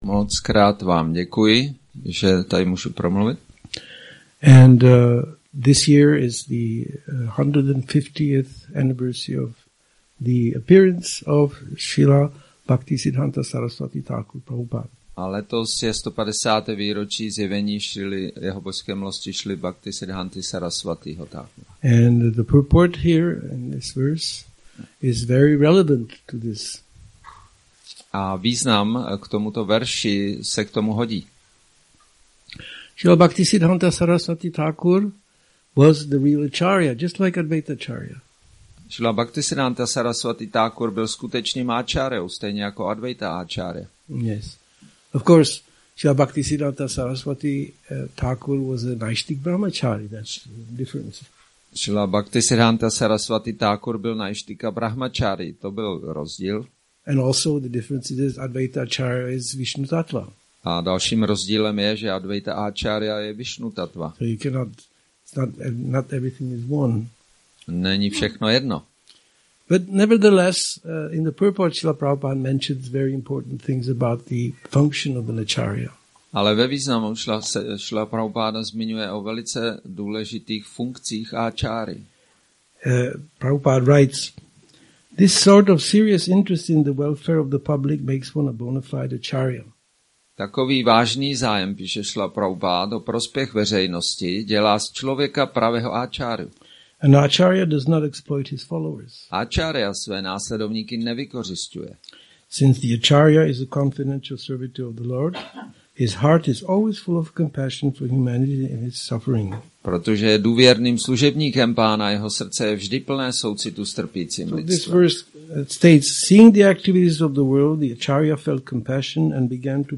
Přednáška CC-ADI-3.98 – Advaita acarya appearance day – Šrí Šrí Nitái Navadvípačandra mandir